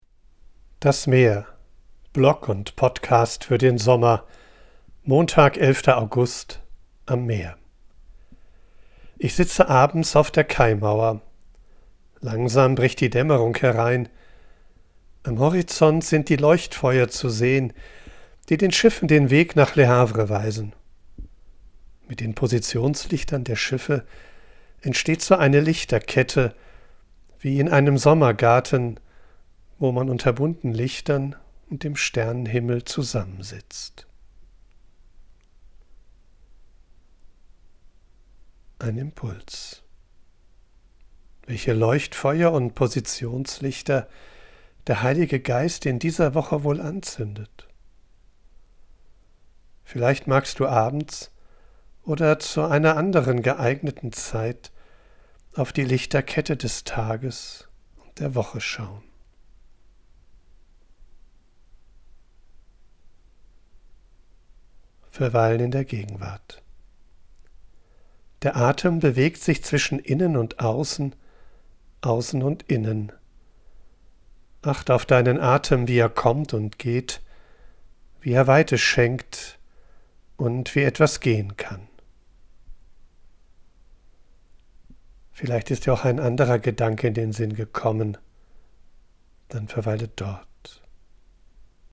live. Ich bin am Meer und sammle Eindrücke und Ideen. Weil ich
von unterwegs aufnehme, ist die Audioqualität begrenzt. Dafür
mischt sie mitunter eine echte Möwe und Meeresrauschen in die